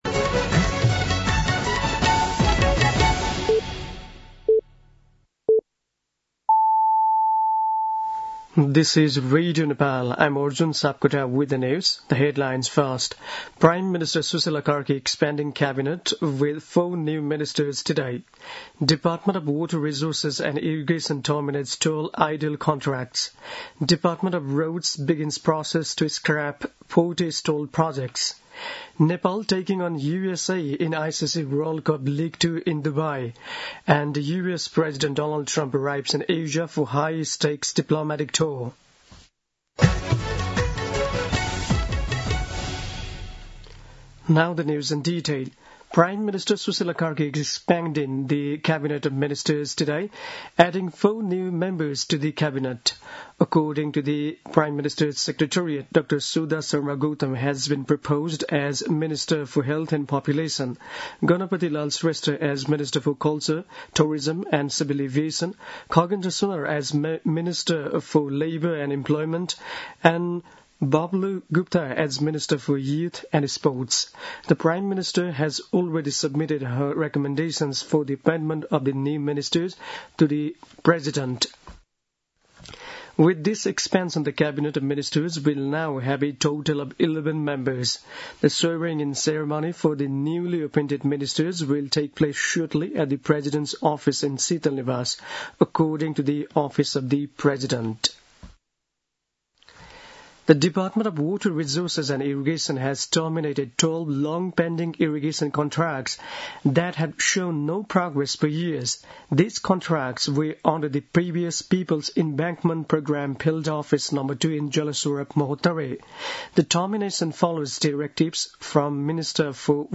दिउँसो २ बजेको अङ्ग्रेजी समाचार : १८ पुष , २०२६
2-pm-English-News-10.mp3